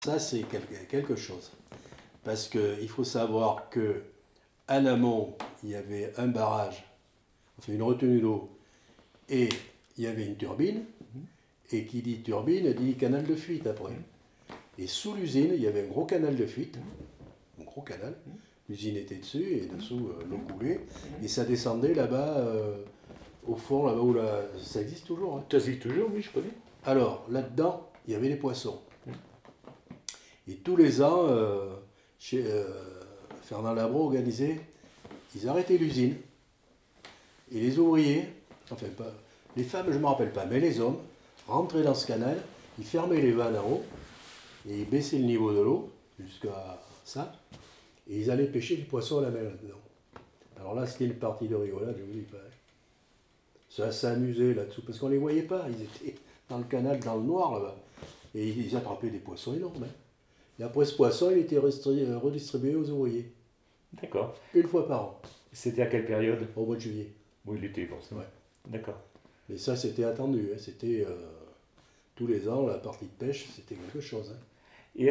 Mémoire de l'usine Labro, interview